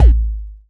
ELECTRO KICK.wav